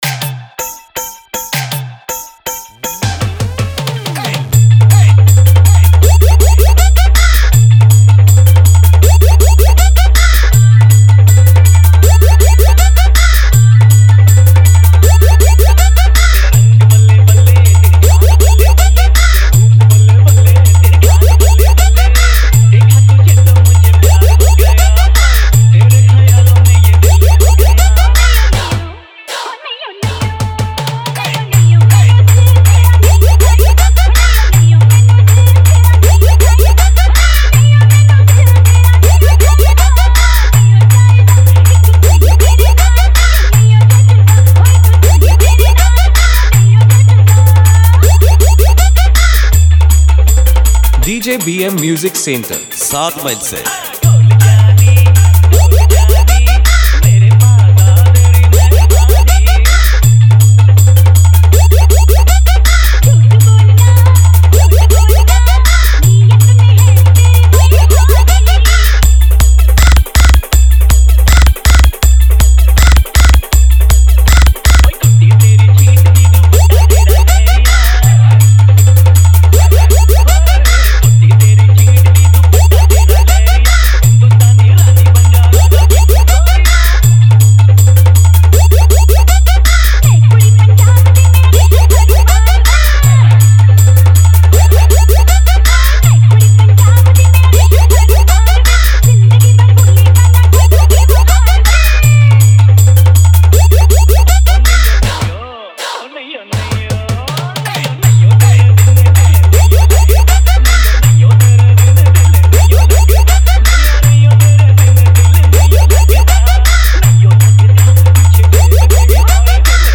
New Style Competition 1 Step Long Humming Pop Bass Mix 2025